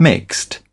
/ˈel.ɪ.dʒə.bl̩/